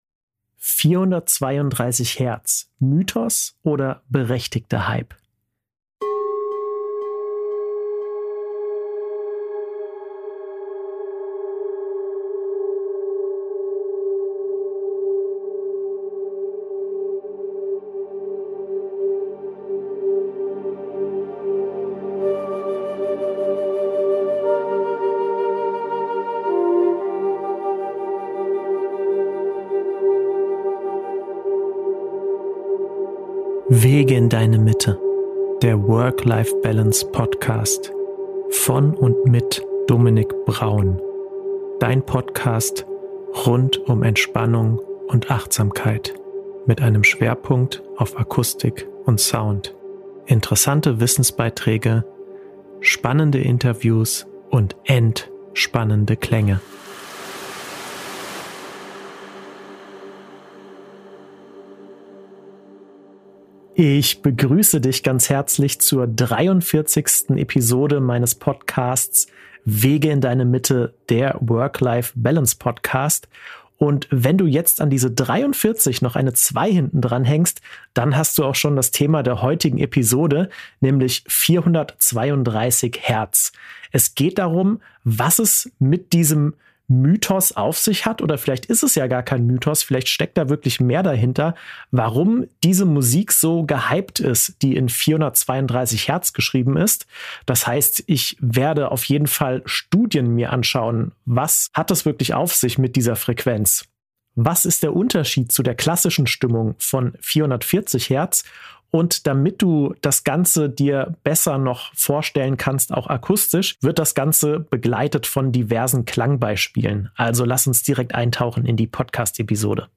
Natürlich arbeite ich auch in dieser Folge wieder mit zahlreichen Klang-Beispielen.